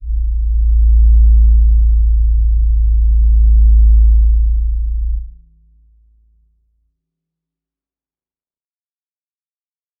G_Crystal-A1-pp.wav